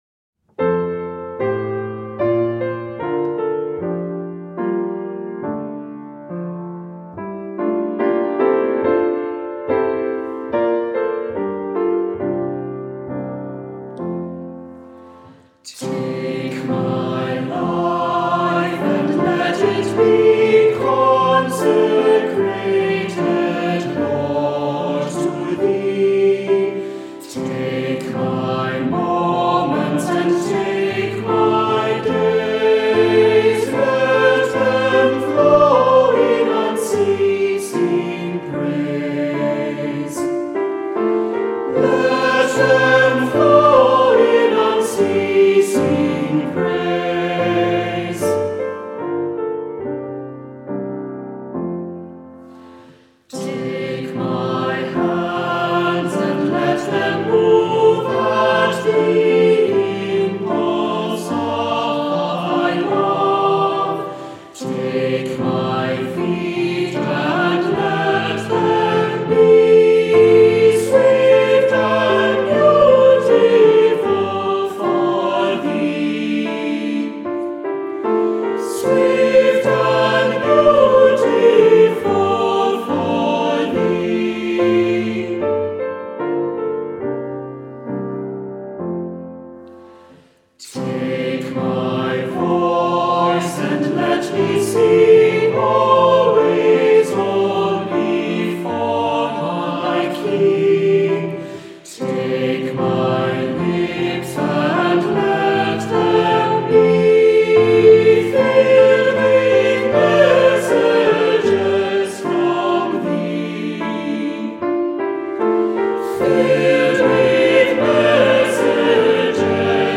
Voicing: Two-part mixed; Assembly